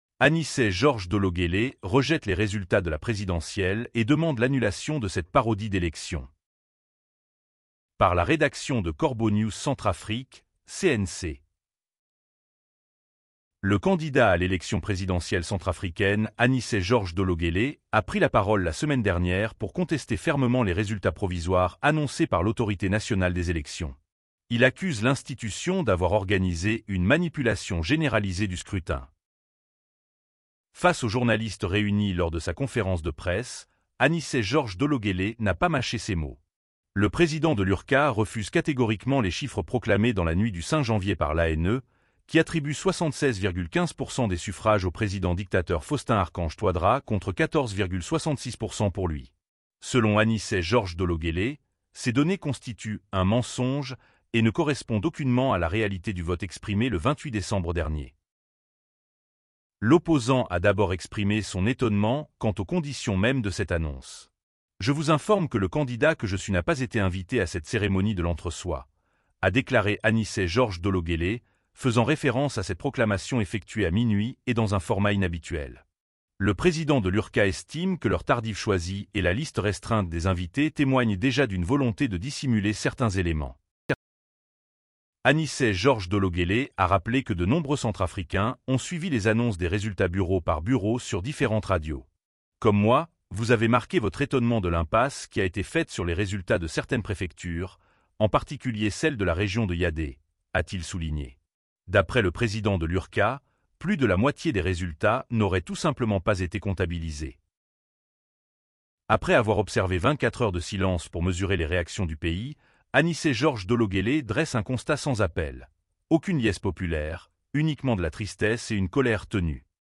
Face aux journalistes réunis lors de sa conférence de presse, Anicet Georges Dologuélé n’a pas mâché ses mots.